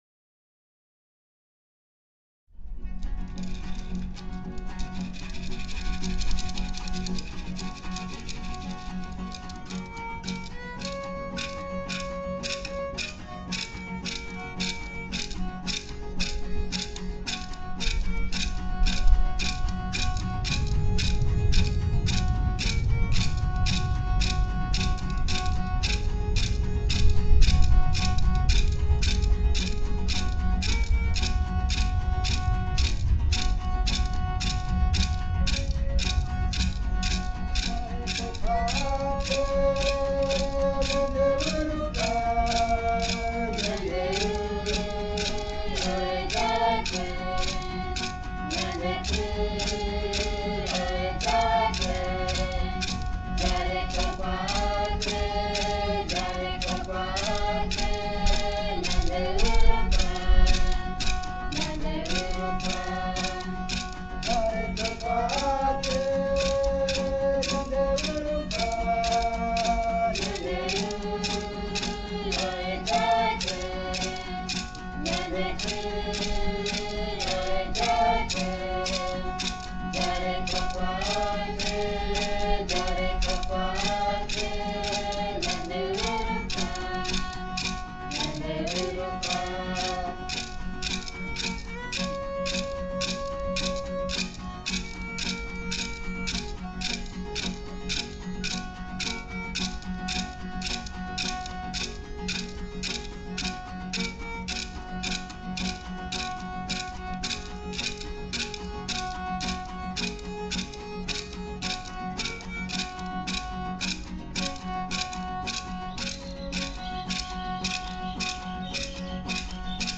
Jareko Kuaa Nhandeyvy Rupa – Coral Guarani Tenonderã